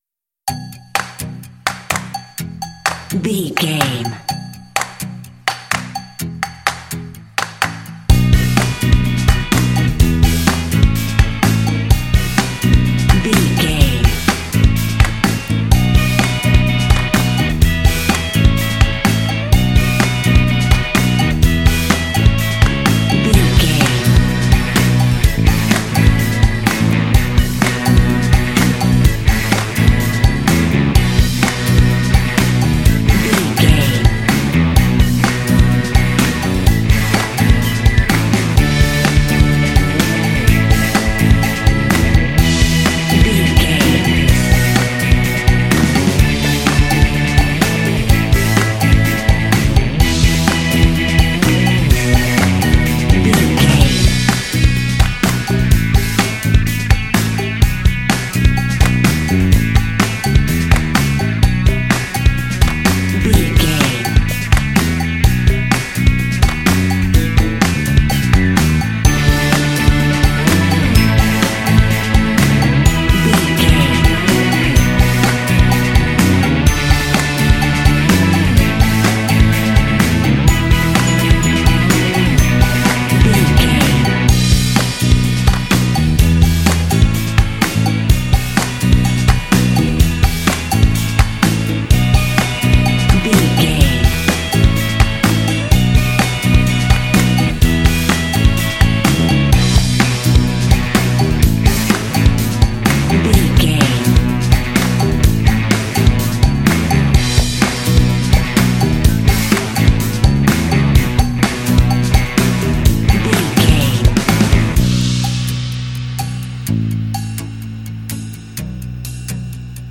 This fun and lighthearted track features a funky organ.
Uplifting
Mixolydian
bouncy
electric guitar
drums
percussion
organ
bass guitar
rock
alternative
indie
blues